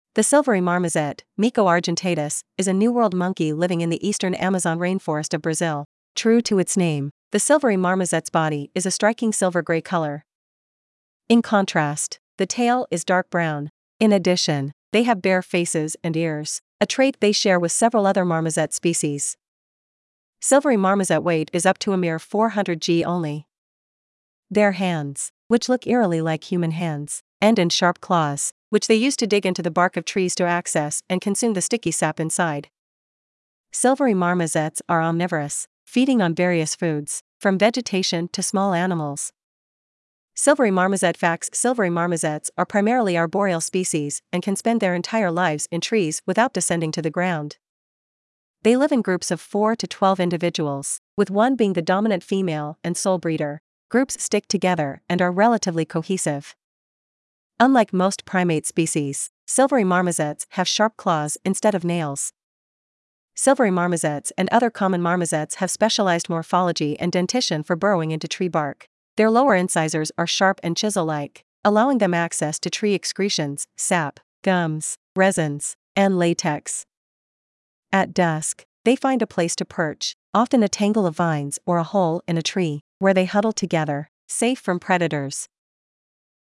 Silvery Marmoset
Silvery-Marmoset.mp3